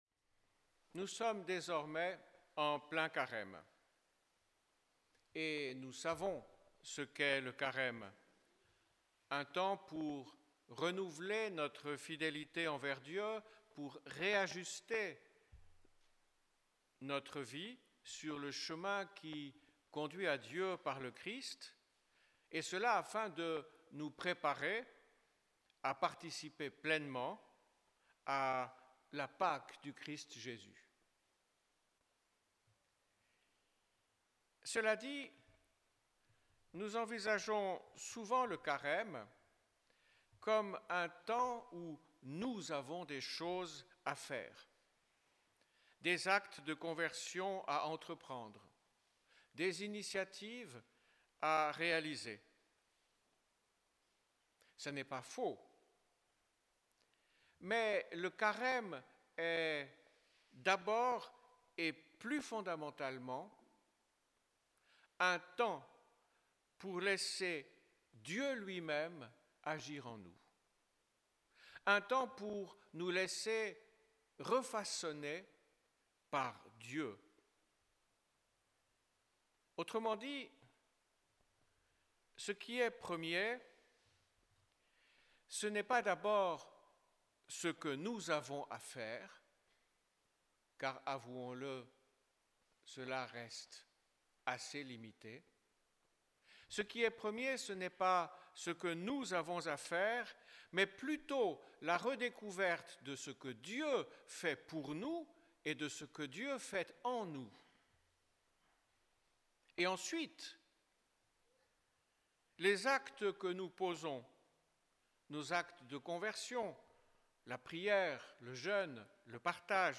Enregistrement en direct : l'homélie